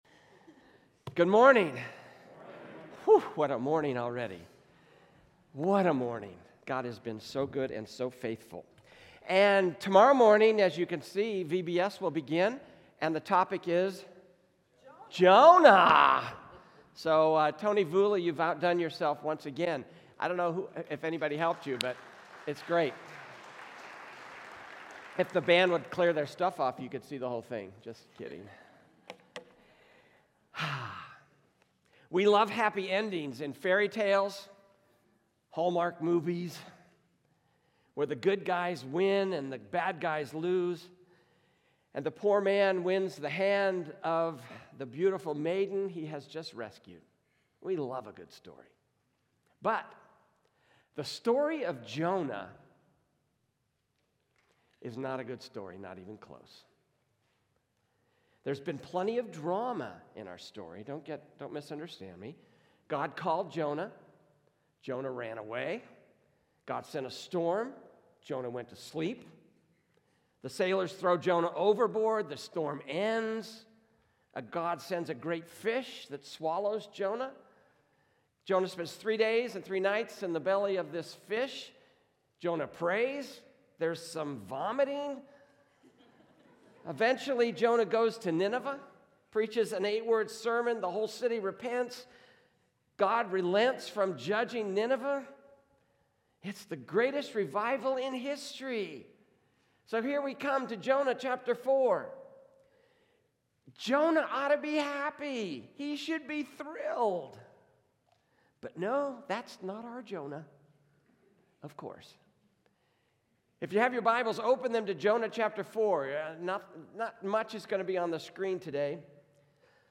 A message from the series "Jonah." We’re kicking off a brand-new series on the book of Jonah—more than just a story about a runaway prophet and a giant fish. It’s a powerful reminder that even when we run, God’s grace runs faster.